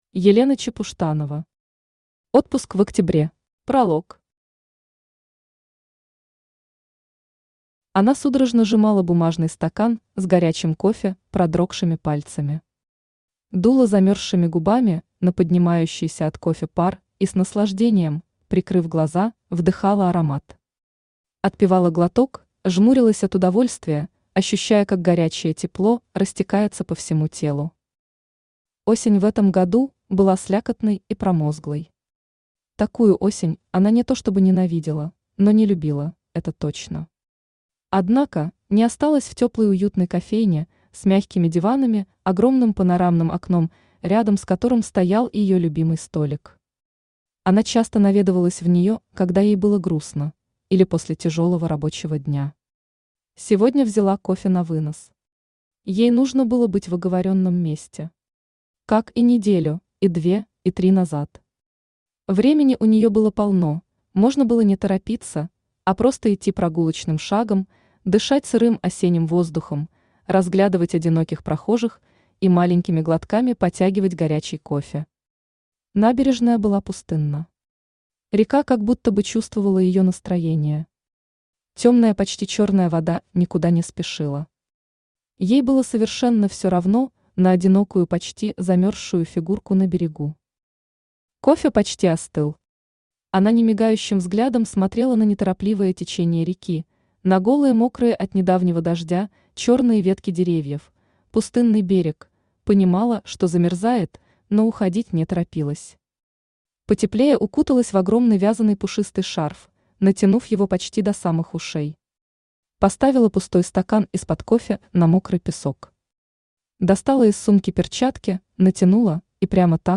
Аудиокнига Отпуск в октябре | Библиотека аудиокниг
Aудиокнига Отпуск в октябре Автор Елена Чепуштанова Читает аудиокнигу Авточтец ЛитРес.